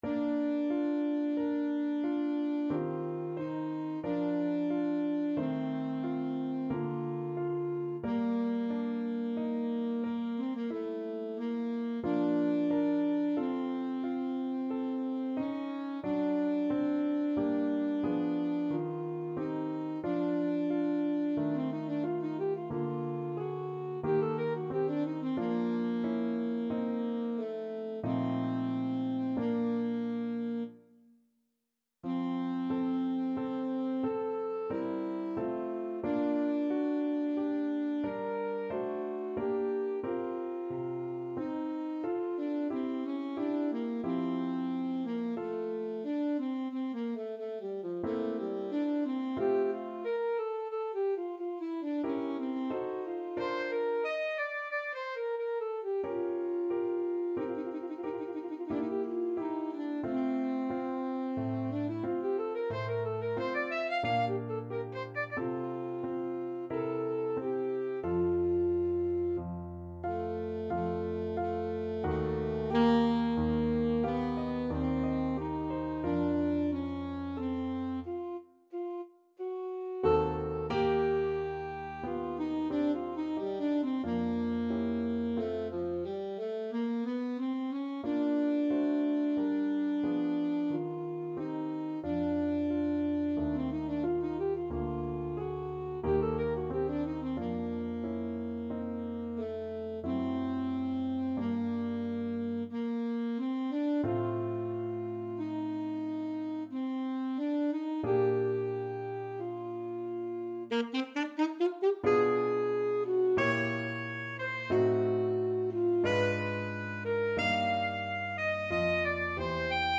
Alto Saxophone
3/4 (View more 3/4 Music)
Adagio =45
Classical (View more Classical Saxophone Music)